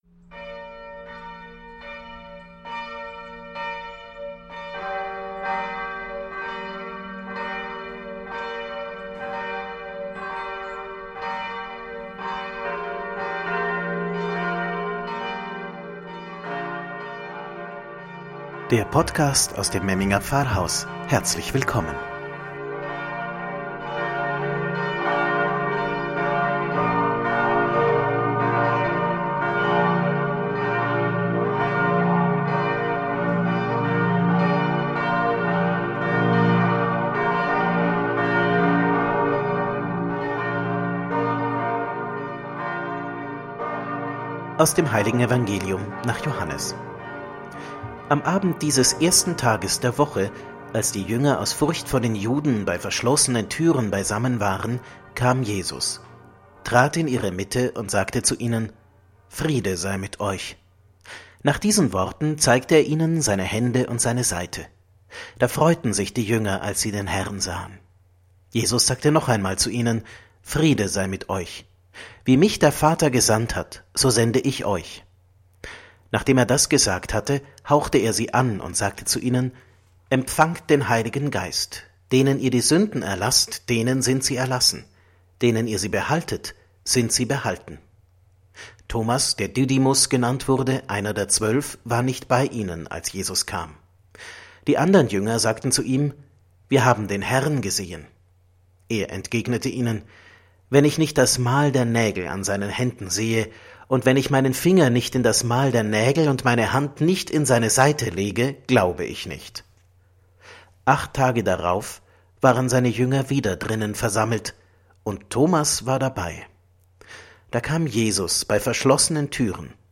„Wort zum Sonntag“ aus dem Memminger Pfarrhaus – Die gute Nachricht für den Weißen Sonntag